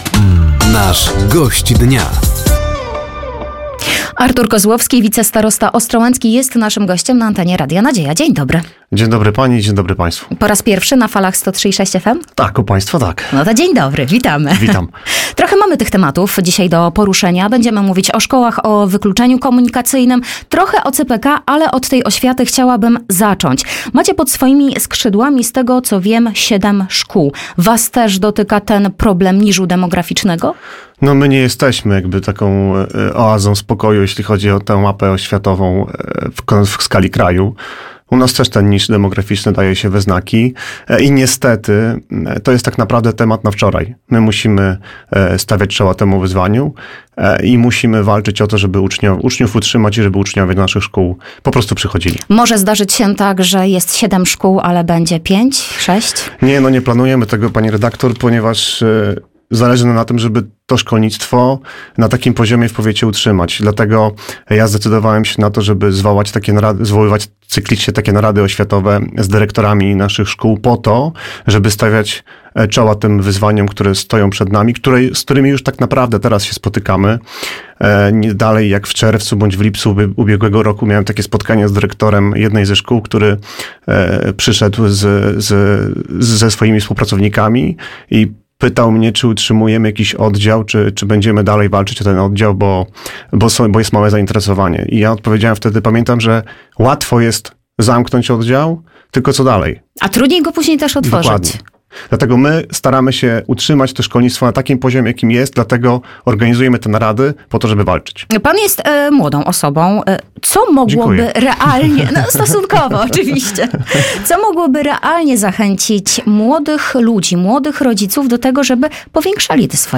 Gościem Dnia w Radiu Nadzieja był wicestarosta ostrołęcki Artur Kozłowski.